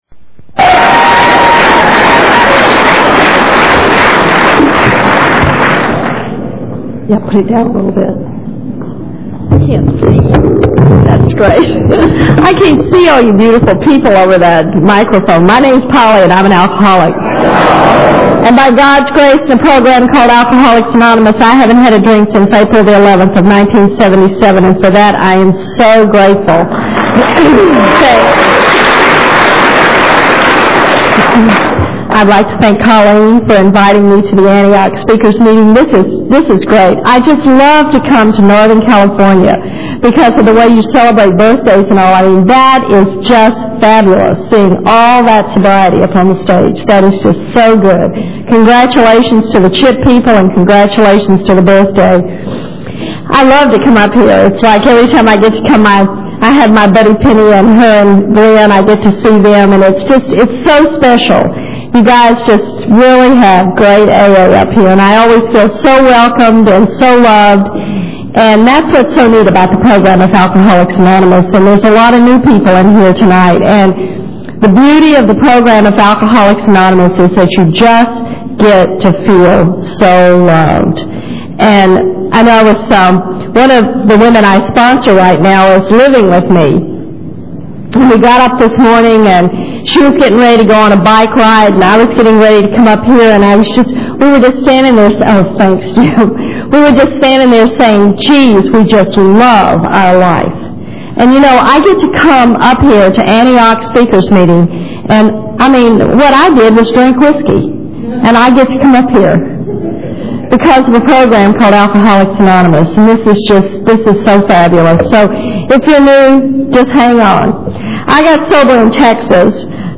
Couch Potato Alcoholic – Women AA Speakers